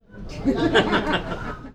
Risas de gente suaves